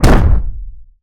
rock_impact_heavy_slam_04.wav